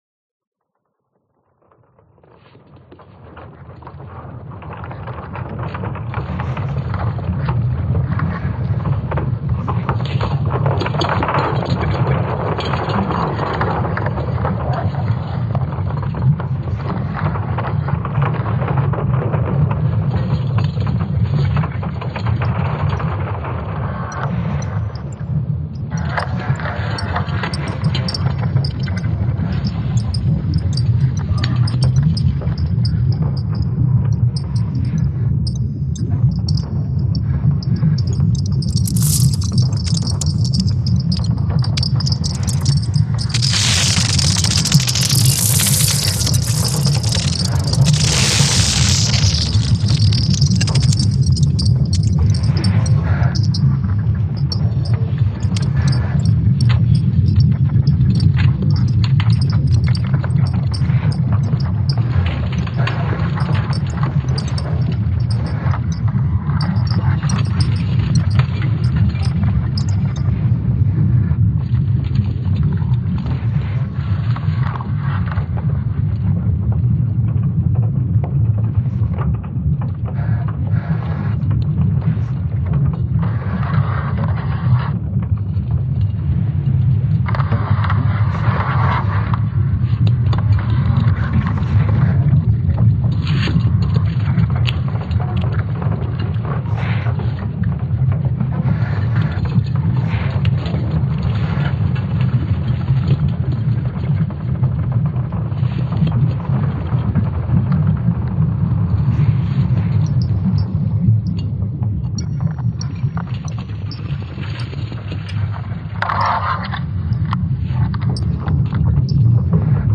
آڈیو: زمینی مقناطیسی فیلڈ کی خوفناک و پُراسرار آوازیں سنیے
ایجنسی کے مطابق ٹیکنیکل یونیورسٹی آف ڈنمارک کے محققین کے مطابق خلائی ایجنسی کے سوارم سیٹلائٹ مشن کے ذریعے مقناطیسی سگنلز کی پیمائش کی گئی جنہیں آواز میں تبدیل کر کے نتائج کا جائزہ لیا گیا، جو بہت خوفناک ہیں۔
ای ایس اے کی جاری کردہ پانچ منٹ کی اس آڈیو میں خوفناک کریکس، کریکنگ آوازیں اور گہری سانس لینے جیسی  آوازیں شامل ہیں۔
magnetic-field-sound.mp3